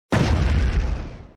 bomb.mp3